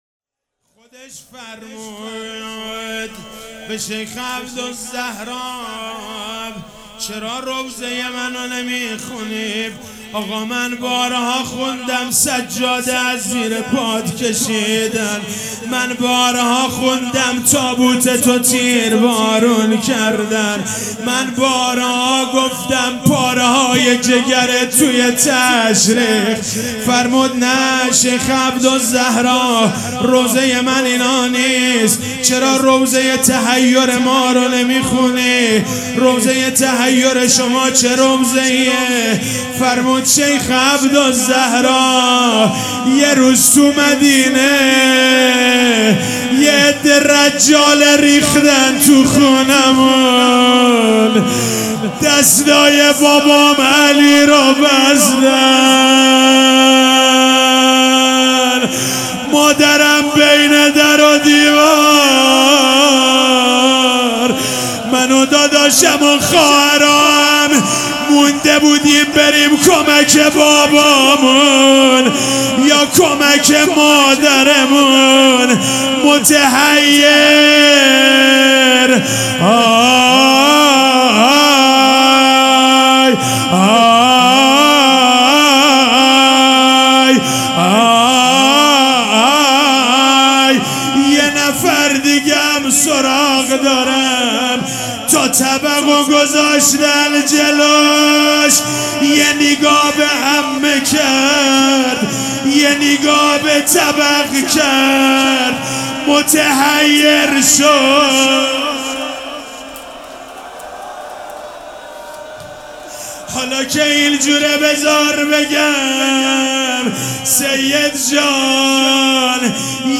روضه
مداح
مراسم عزاداری شب پنجم